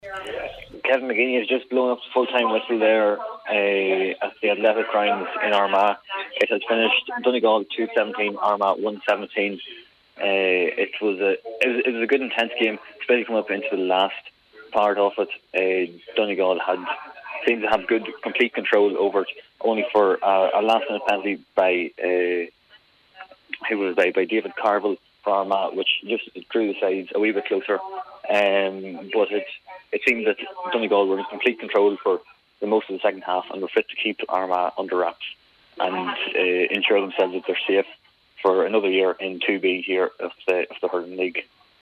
full-time report